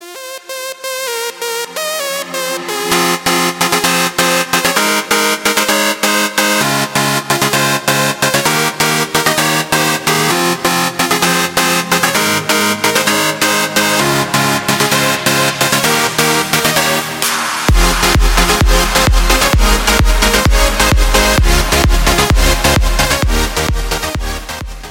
Categoria Alarmes